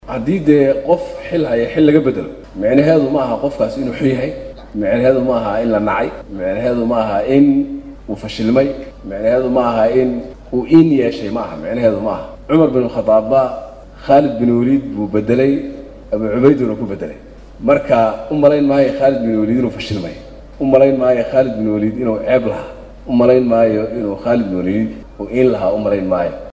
Munaasabadda xil wareejinta ayaa waxaa goobjoog ahaa ra’iisul wasaaraha Soomaaliya, Xamsa Cabdi Barre, wasiirka amniga gudaha, xildhibaanno, saraakiil iyo marti sharaf kale.